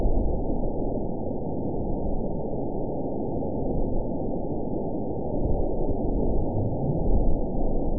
event 920830 date 04/10/24 time 23:17:49 GMT (1 year, 1 month ago) score 7.37 location TSS-AB04 detected by nrw target species NRW annotations +NRW Spectrogram: Frequency (kHz) vs. Time (s) audio not available .wav